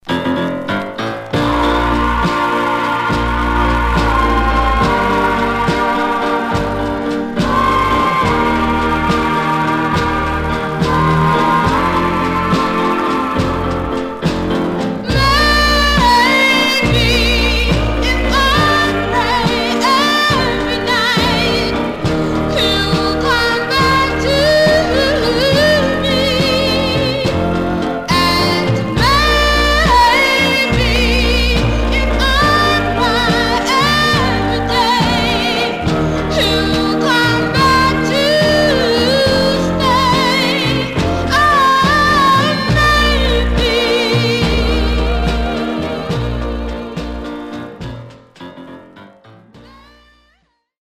Surface noise/wear
Mono
Black Female Group